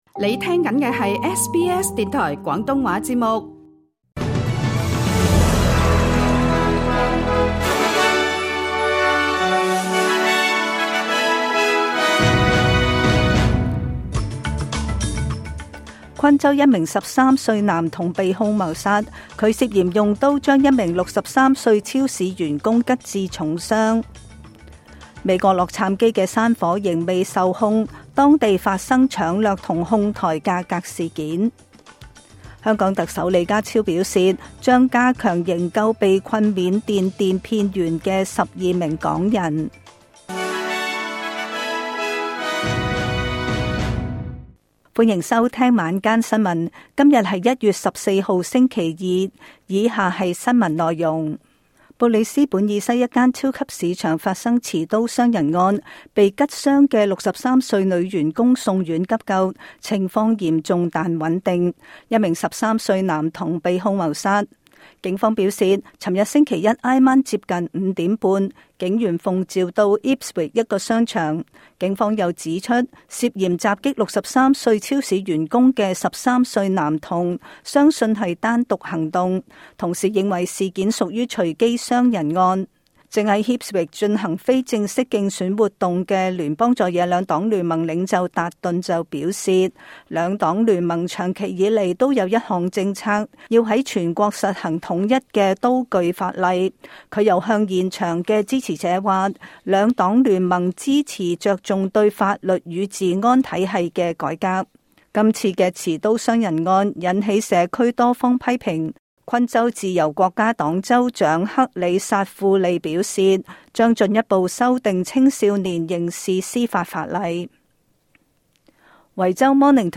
請收聽本台為大家準備的每日重點新聞簡報。
SBS廣東話晚間新聞